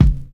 Dusty Kick 04.wav